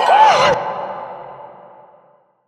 Vox
TM88 TMVox.wav